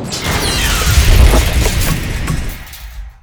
escape3.wav